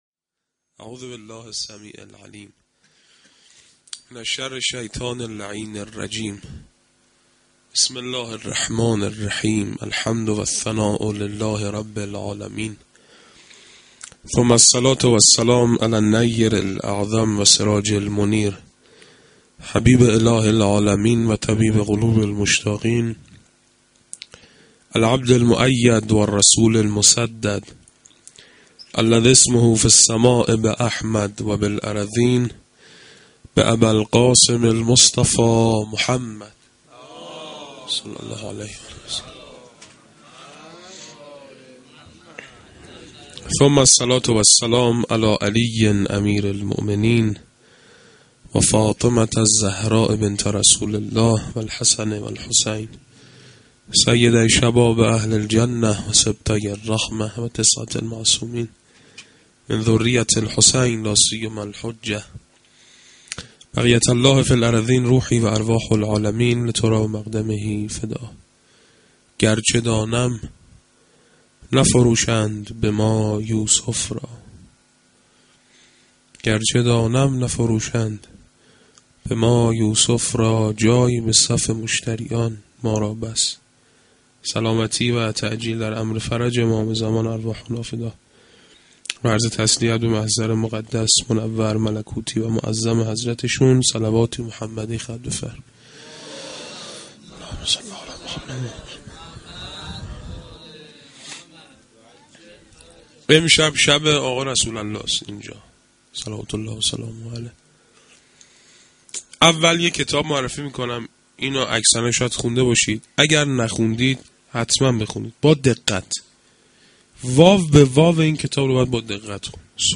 sa92-sh1-Sokhanrani.mp3